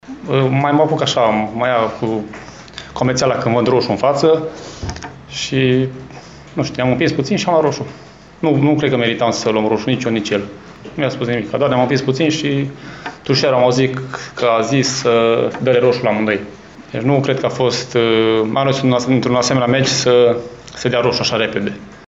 Momentul acesta a fost comentat și la declarațiile de după meci de unul dintre împricinați